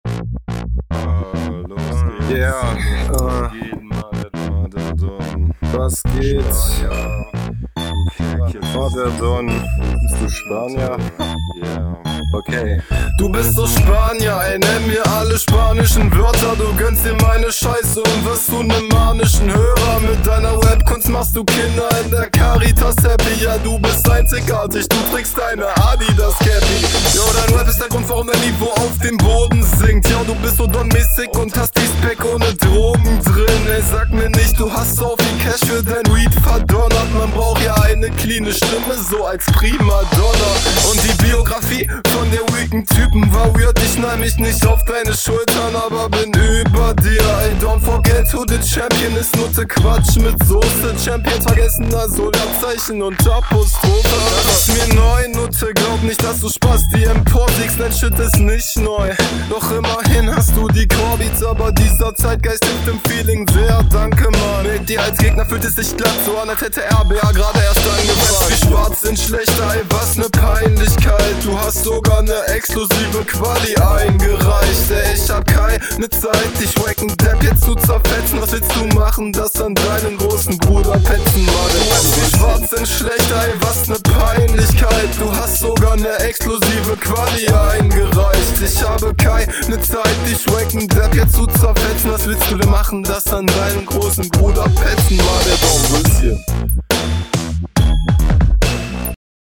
Beat auch hier nicht so meins.
Schöner Einstieg, auf dem Beat kommst du mit deinem Style direkt viel besser.